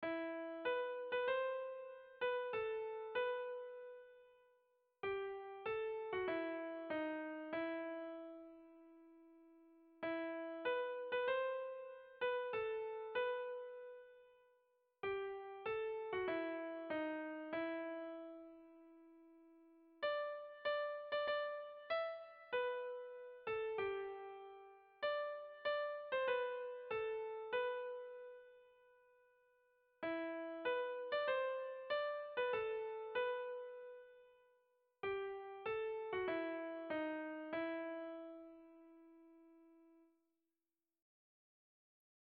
Kontakizunezkoa
Zortziko txikia (hg) / Lau puntuko txikia (ip)
AABA